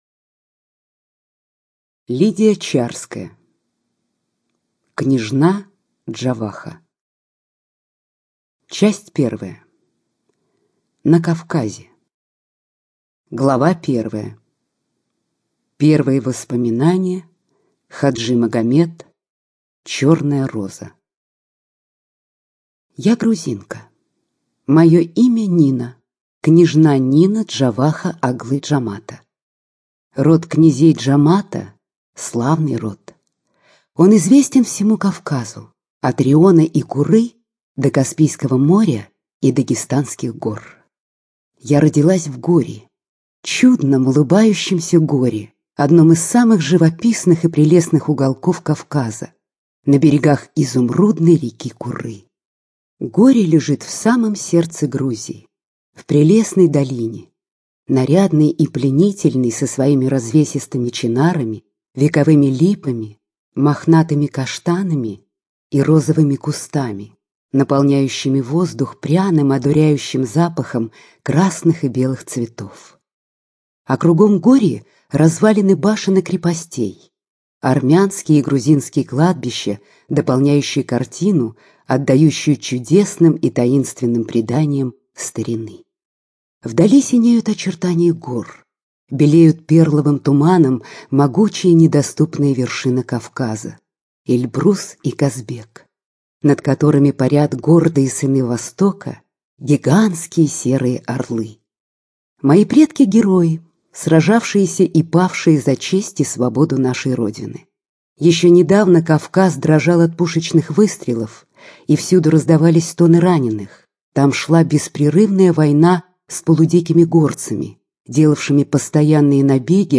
ЖанрДетская литература, Классическая проза